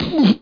1 channel
punch02.mp3